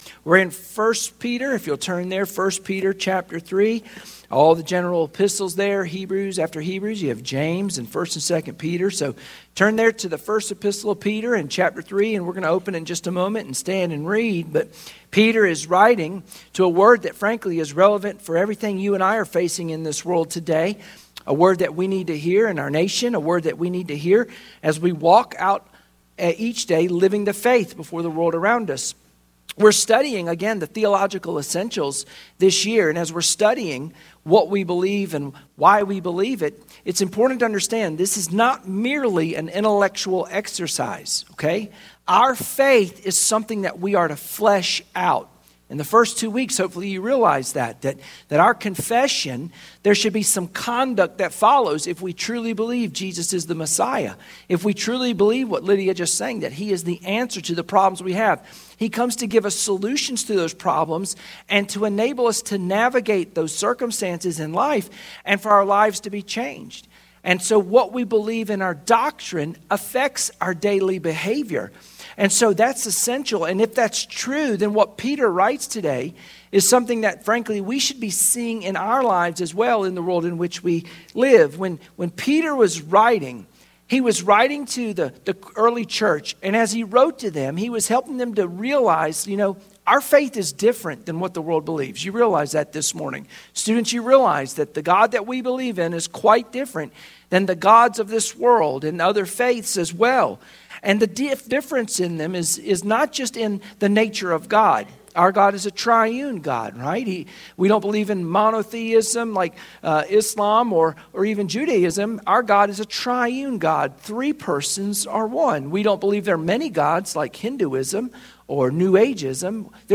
Sunday Morning Worship Passage: 1 Peter 3:13-17 Service Type: Sunday Morning Worship Share this